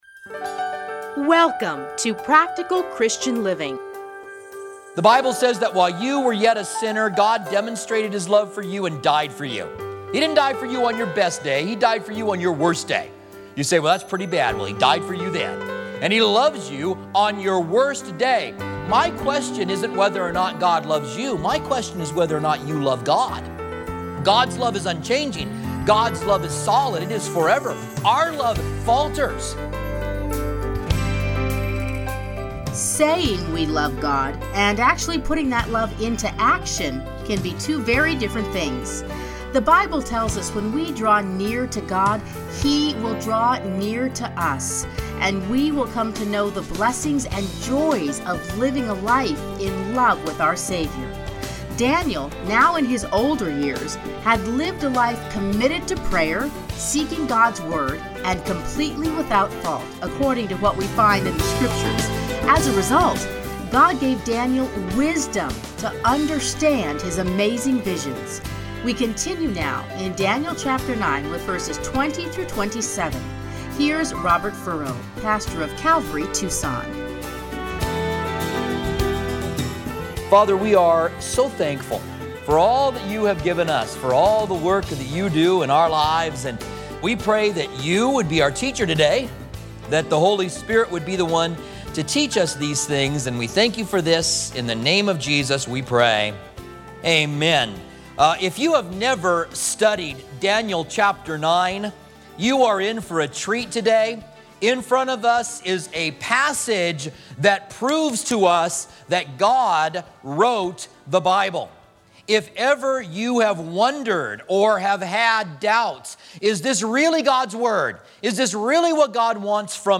teachings are edited into 30-minute radio programs titled Practical Christian Living